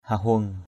/hah-hʊəŋ/ (t.) hở hang = vide, non employé. cuk anguei hahueng c~K az&] hh&$ ăn mặc hở hang.
hahueng.mp3